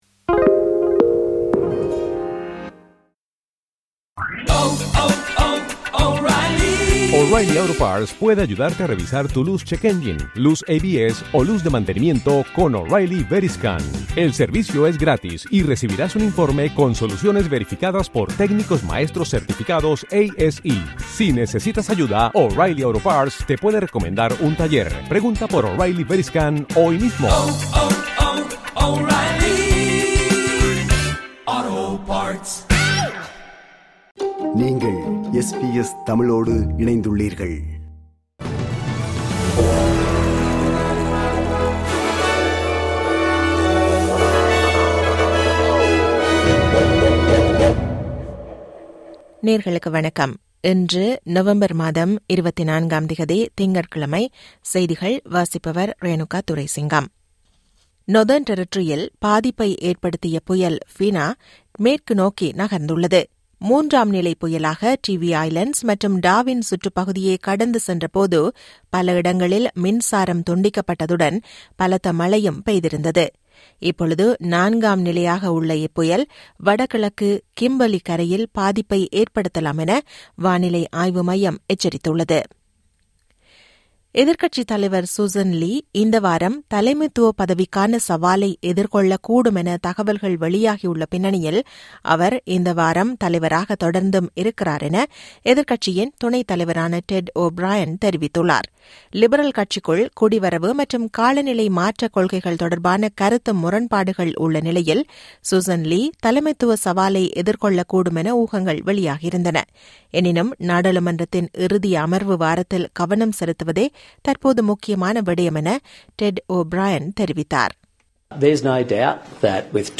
இன்றைய செய்திகள்: 24 நவம்பர் 2025 - திங்கட்கிழமை
SBS தமிழ் ஒலிபரப்பின் இன்றைய (திங்கட்கிழமை 24/11/2025) செய்திகள்.